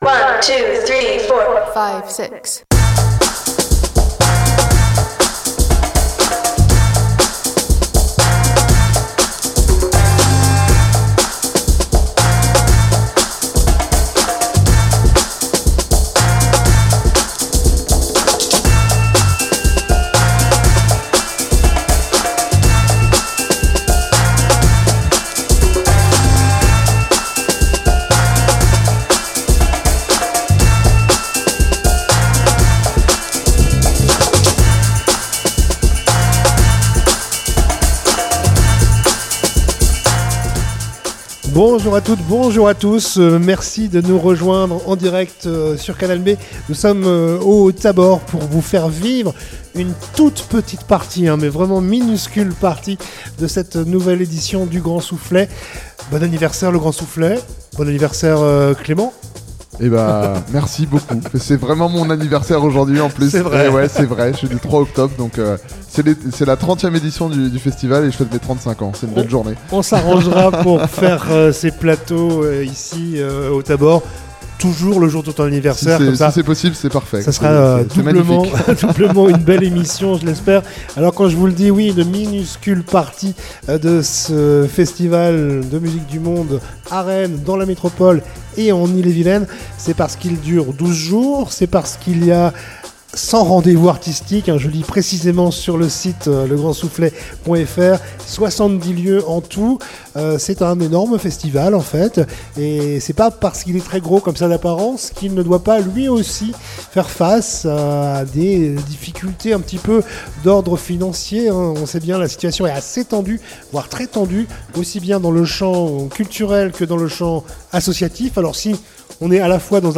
Le Grand Soufflet en direct du Village Thabor
Émission spéciale Le Grand Soufflet en direct du Village Thabor.